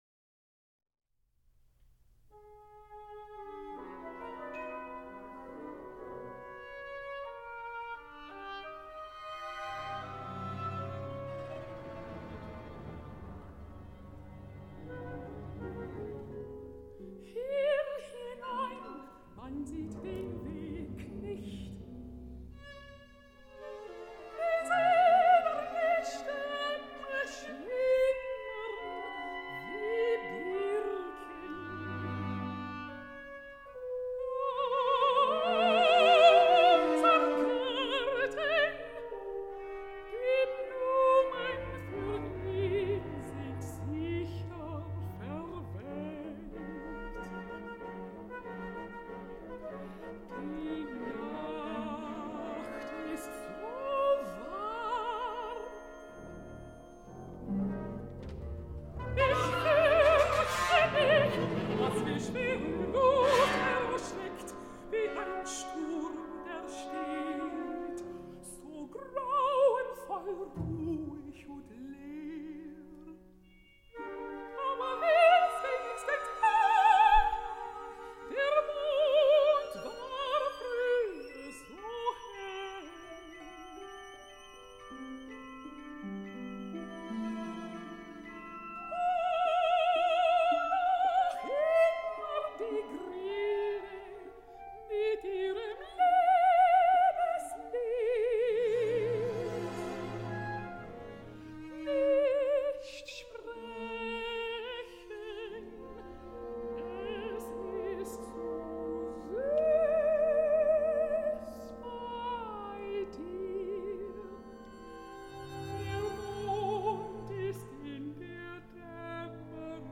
Performance of Erwartung (Jesse Norman)